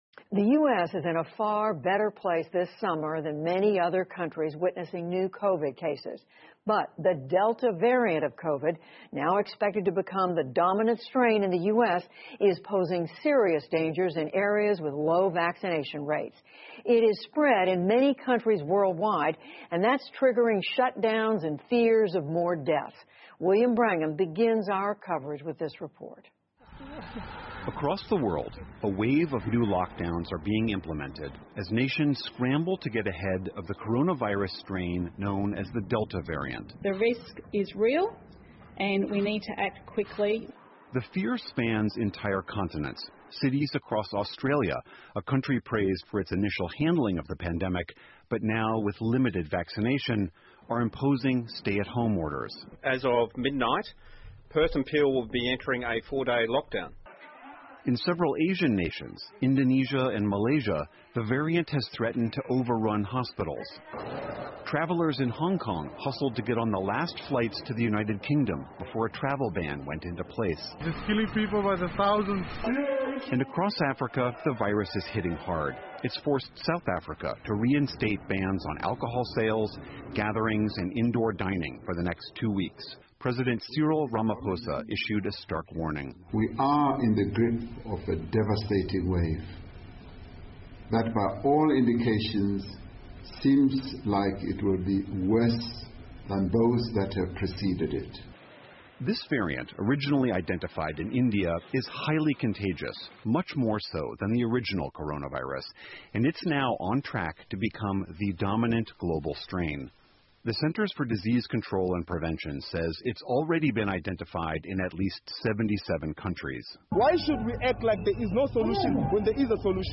在线英语听力室PBS高端访谈:的听力文件下载,本节目提供PBS高端访谈健康系列相关资料,内容包括访谈音频和文本字幕。